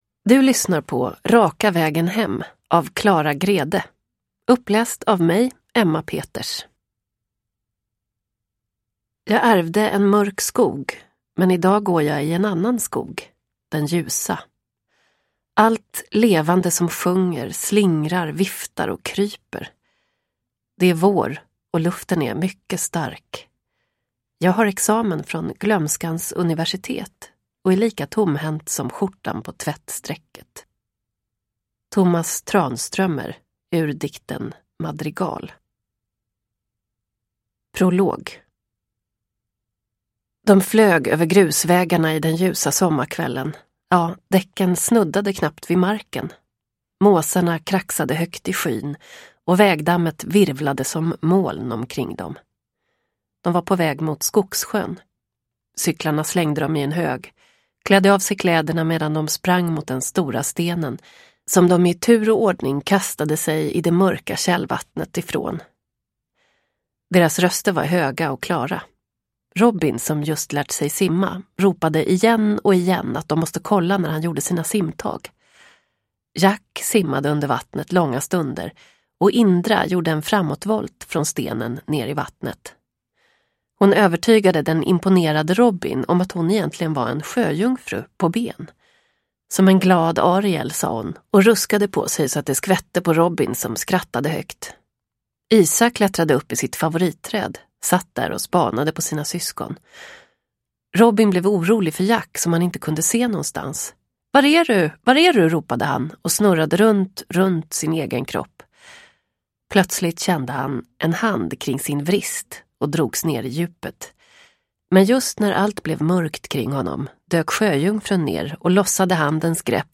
Raka vägen hem – Ljudbok – Laddas ner